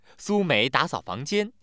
happy